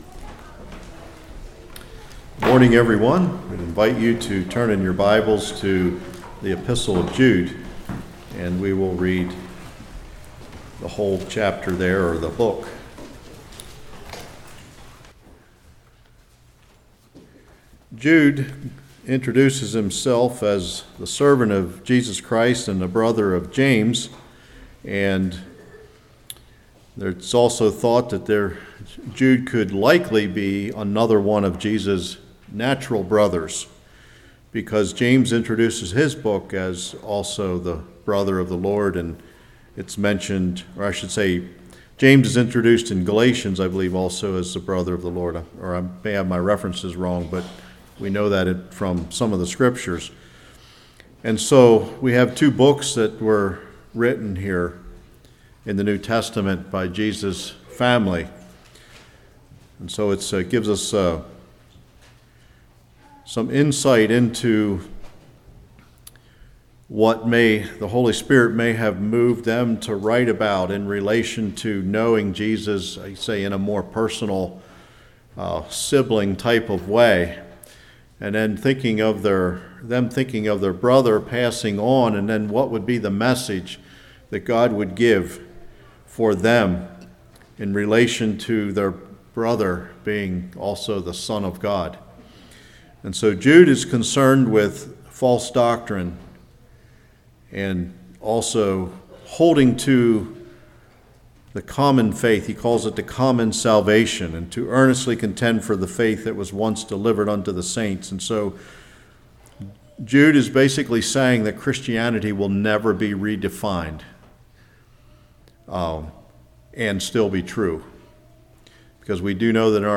Jude 1-25 Service Type: Morning Jude 3-5 The Call of God to Hold Fast Heb. 10:22-25-Example of the Children of Israel James 1:12-Example of Lot and his wife.